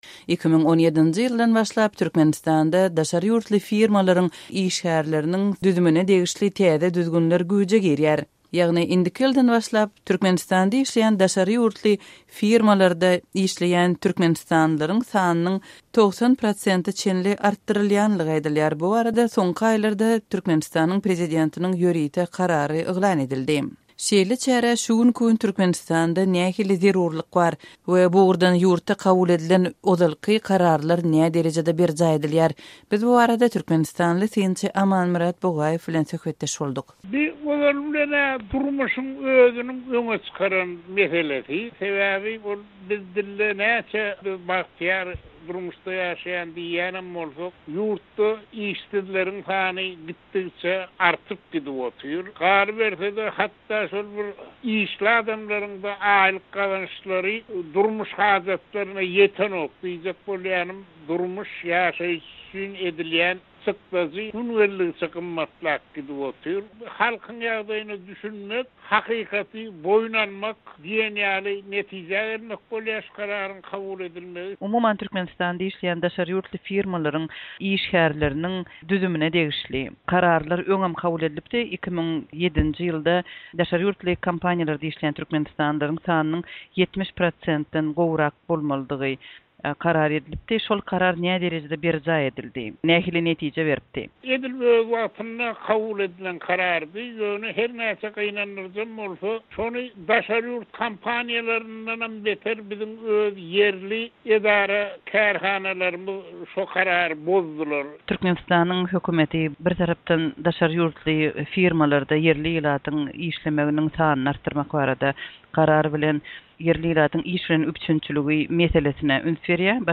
Azatlyk Radiosy öz sowallary bilen türkmenistany synçy